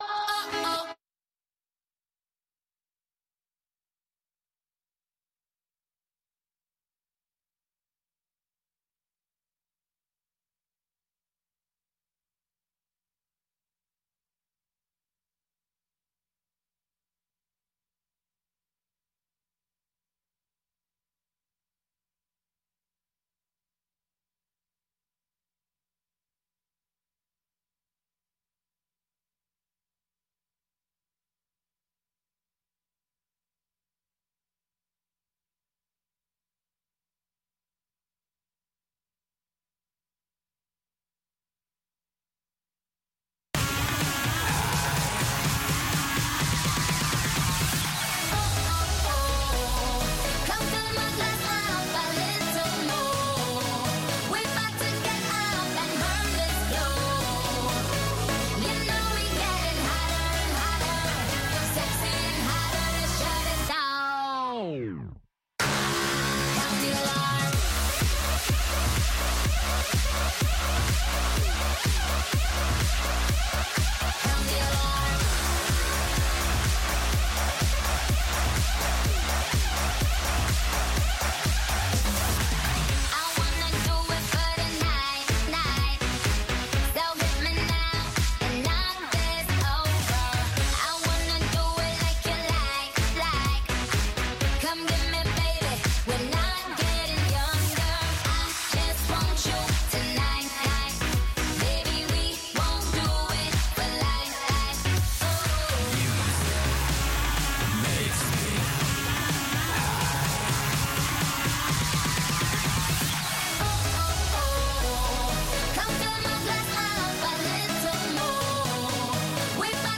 from N’dombolo to Benga to African Hip Hop
pan-African music